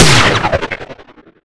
rifle_alt_firev7.wav